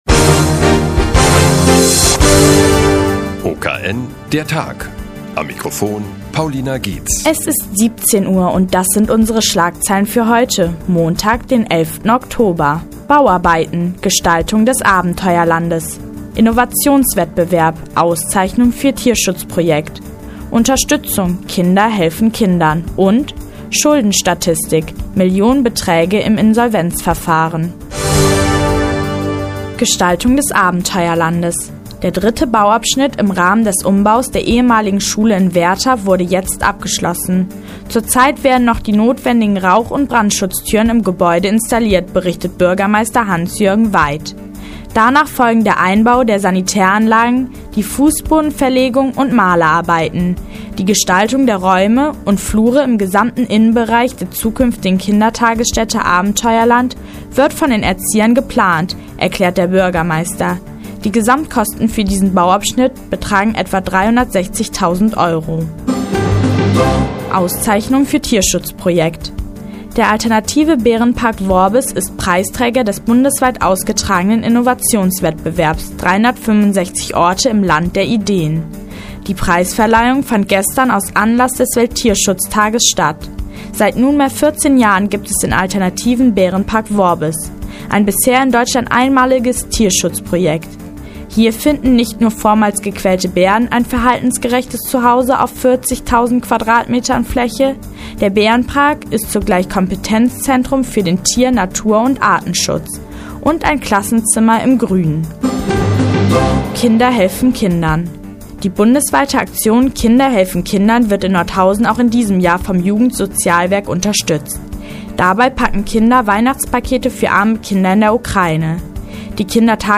Die tägliche Nachrichtensendung des OKN ist nun auch in der nnz zu hören.